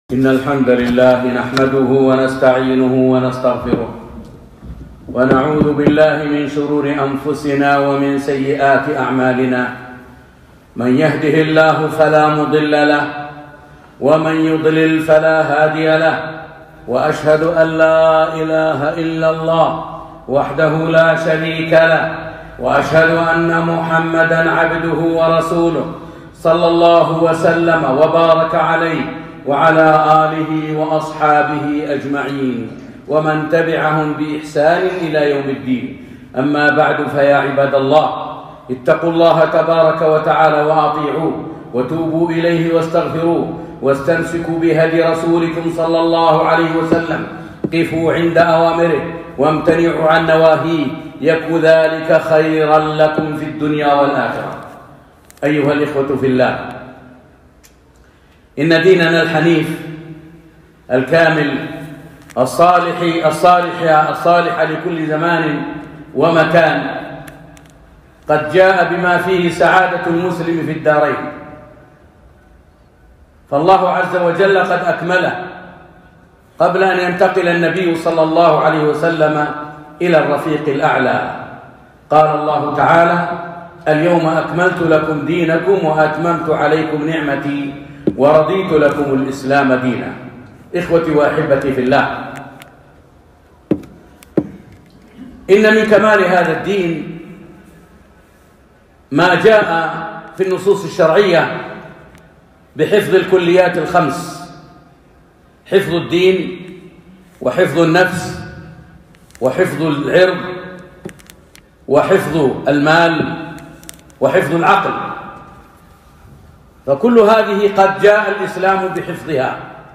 خطبة - خطورة الآبار المكشوفة